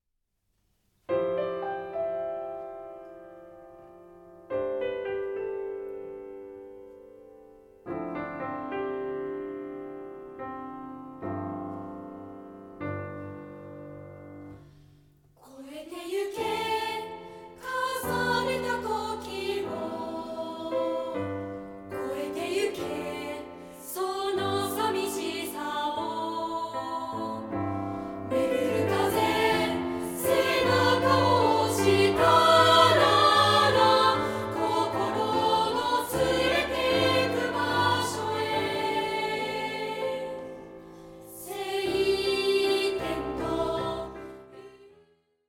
同声2部合唱／伴奏：ピアノ